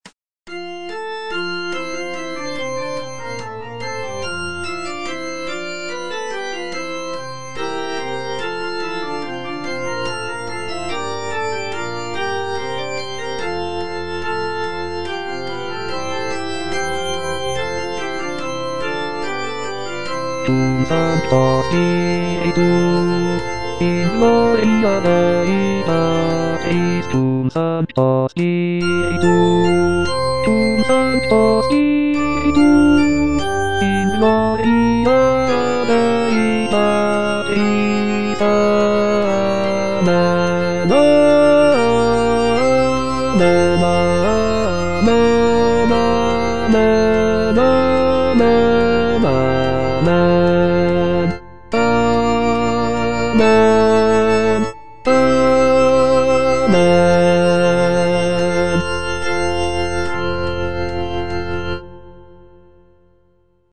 T. DUBOIS - MESSE IN F Cum sancto spiritu - Bass (Voice with metronome) Ads stop: auto-stop
It is a setting of the traditional Catholic Mass text in the key of F major.